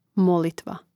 mòlitva molitva